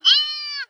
AV_deer_exclaim.wav